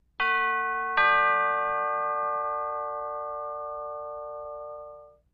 Door Bells; Household Door Bells 1